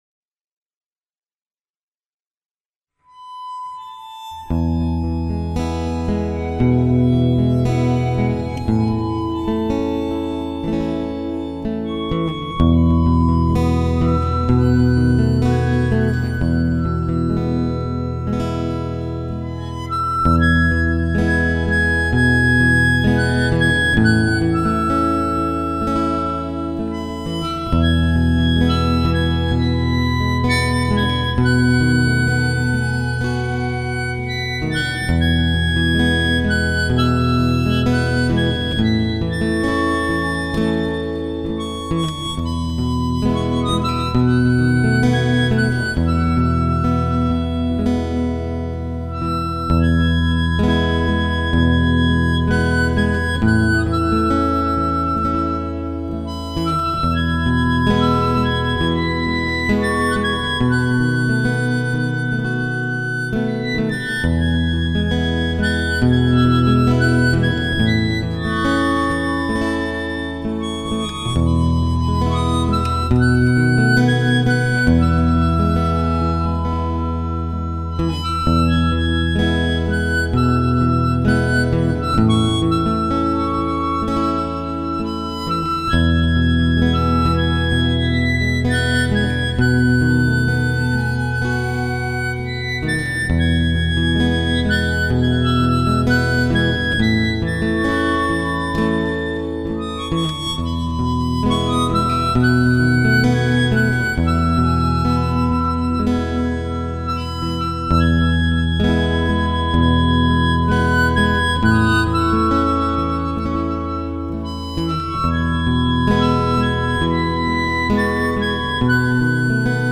在这酒足饭饱心满意足有能量有时间熬夜录歌的感恩节之夜